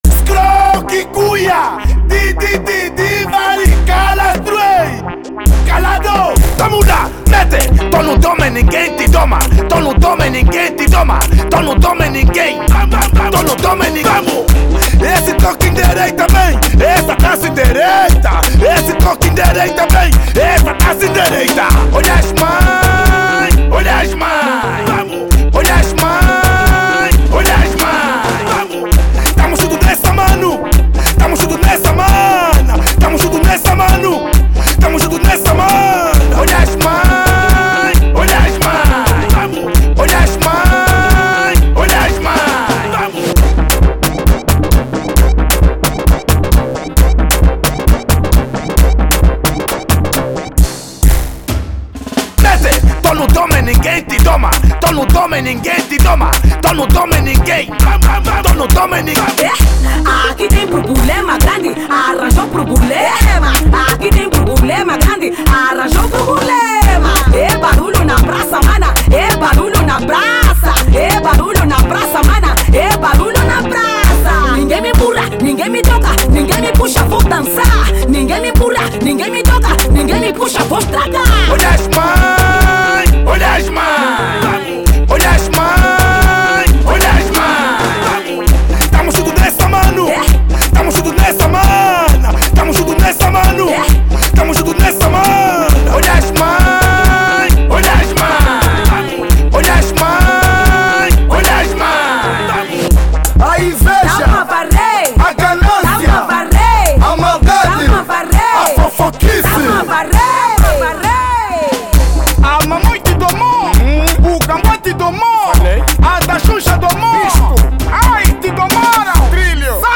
Genero: Kuduro